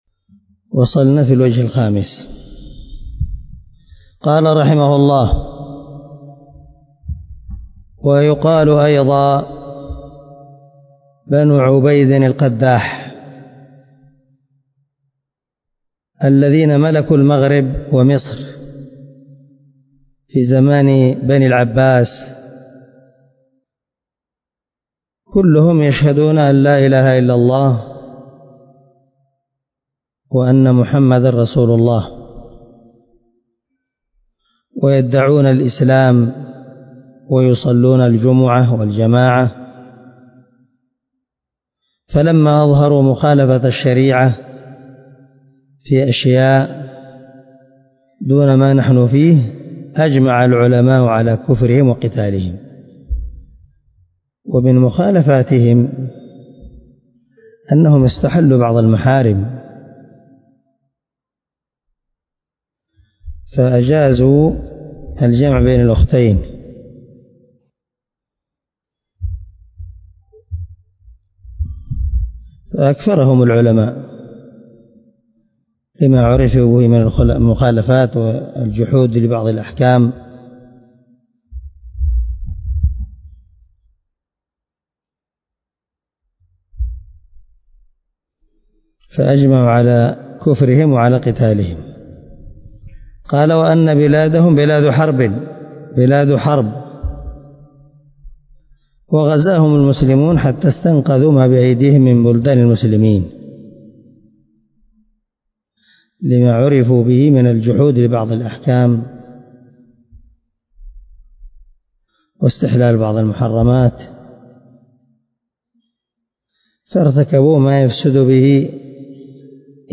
شرح كشف الشبهات 0017 الدرس 16 من شرح كتاب كشف الشبهات